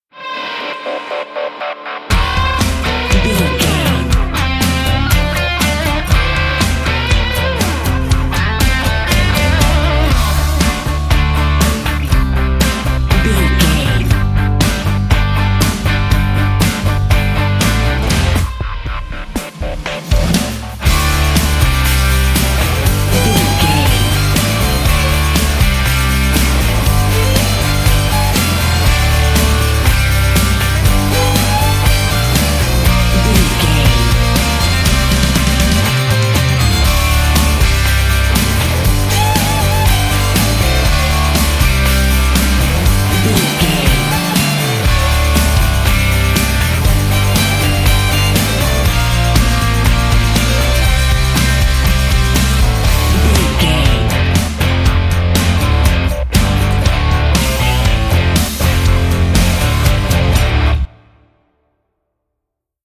Aeolian/Minor
drums
electric guitar
bass guitar
violin
country rock